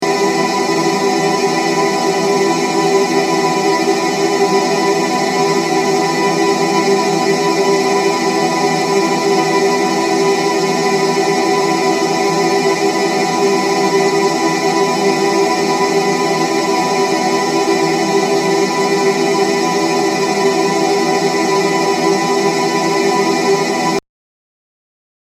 Since you want to generate a sustained sound from a single shot, you need to activate the freeze option while the chord is playing the portion of sound that you want to freeze.
Try different delay settings in order to take different portions of the sound but keep the two faders almost at the same value to have a smooth effect, same thing for the amplitude.
I’ve set it up like in the image, play the audio clip and listen to the effect.